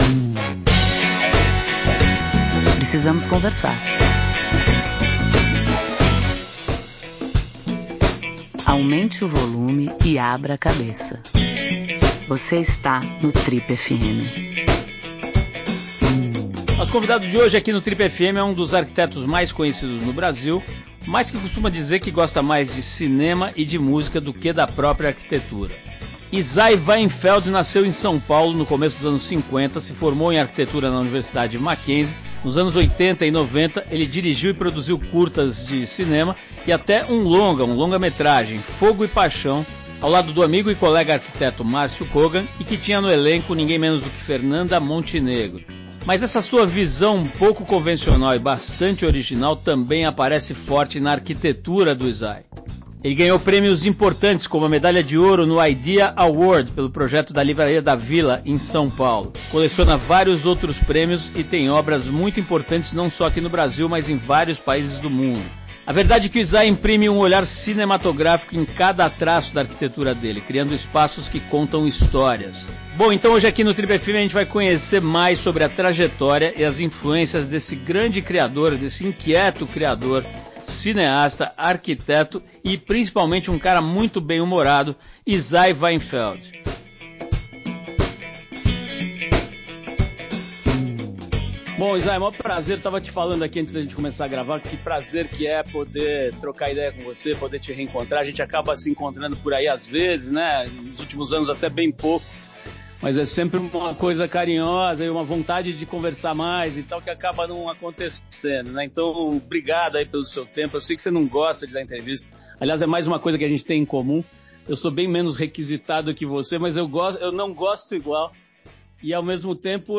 Crítico, arquiteto bateu um papo sobre arte, empreendedorismo, sociedade e seu incômodo com falta de preocupação cultural no mercado